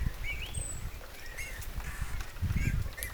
punatulkun huikahdusääni, 1
tuollainen_huikahdus_punatulkun_aani.mp3